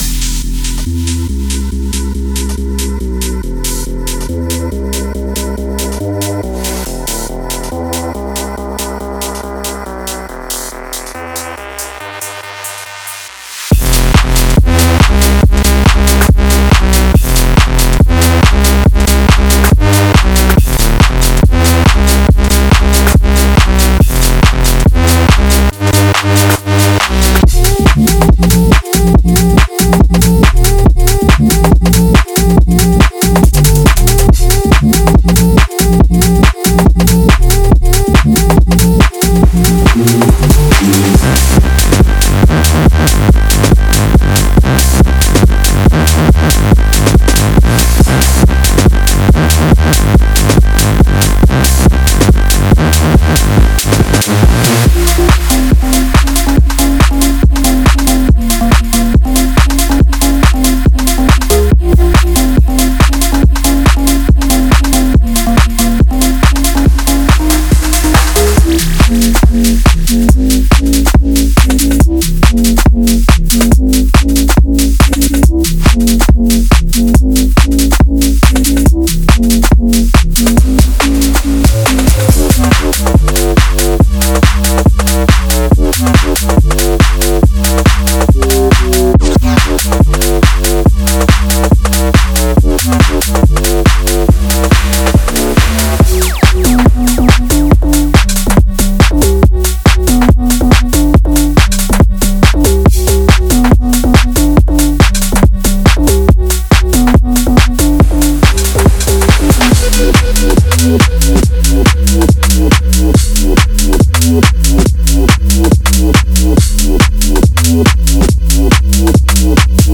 Genre:Garage
内部には、重量感のあるベースライン、スウィングするドラムグルーヴ、そして展開を加速させる多彩なFXが収録されています。
デモサウンドはコチラ↓